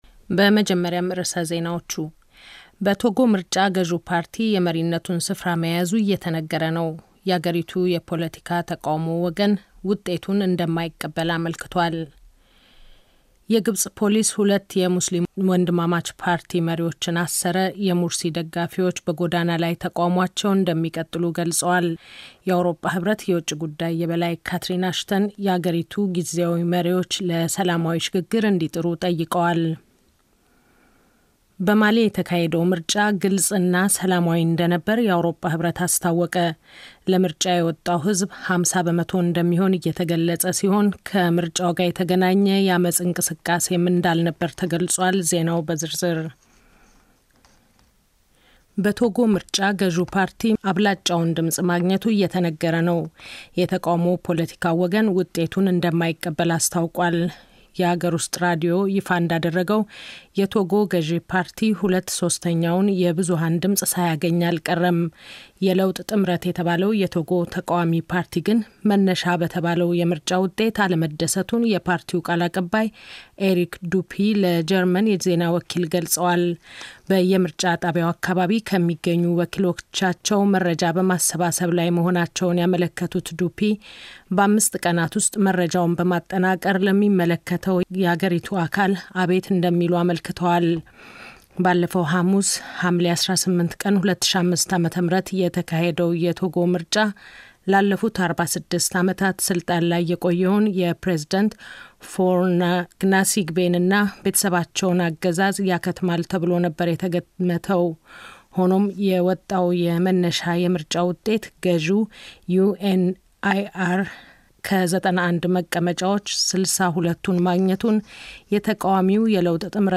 29.07.2013 ዜና 16:00 UTC